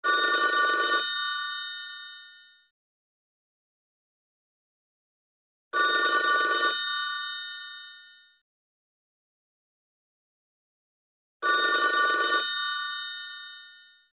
Android, Klassisk Telefon, Klassisk